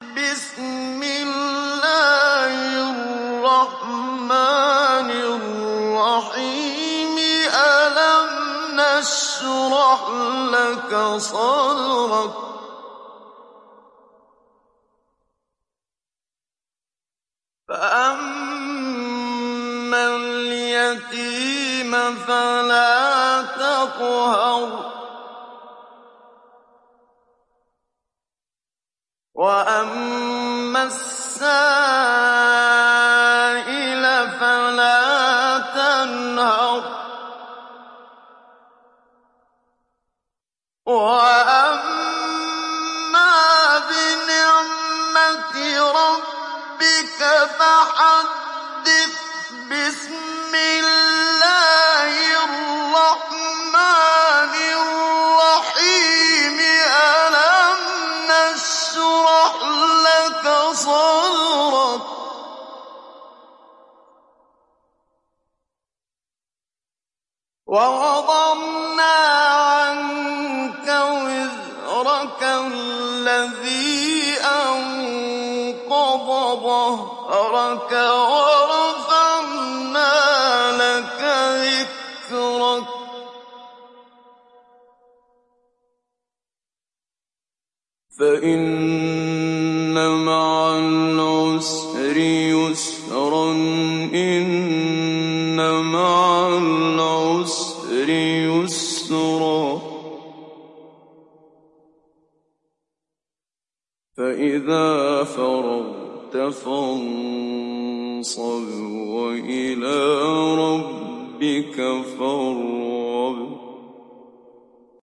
Télécharger Sourate Ash Sharh Muhammad Siddiq Minshawi Mujawwad